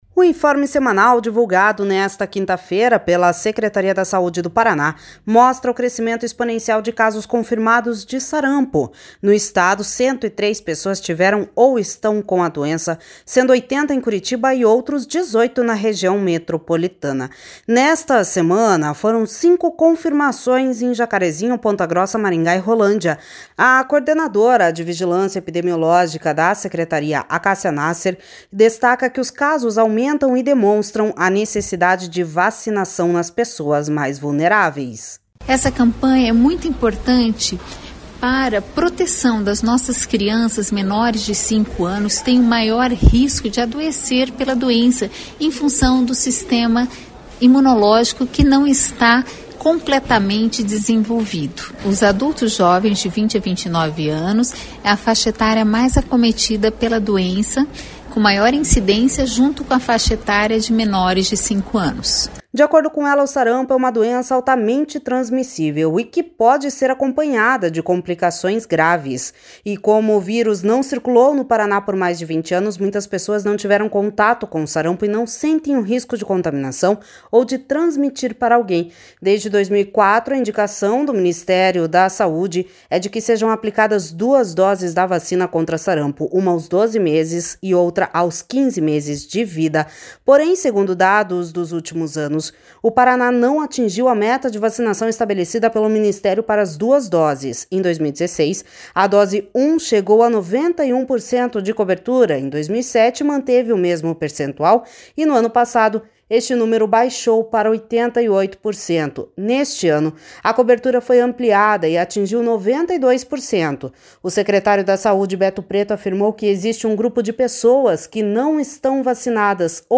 O secretário da Saúde, Beto Preto, afirmou que existe um grupo de pessoas que não estão vacinadas ou por falta de informação e consciência sobre o tema, ou por resistência à vacina.// SONORA BETO PRETO//